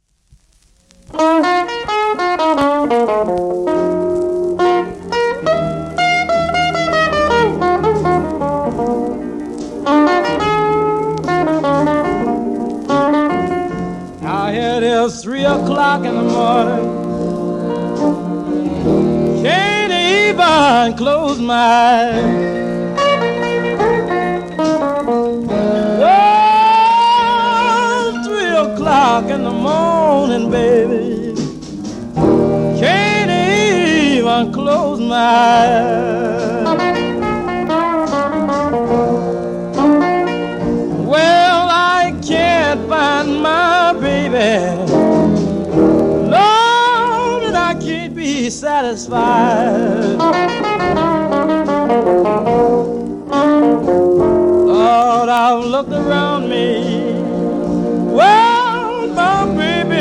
1951年録音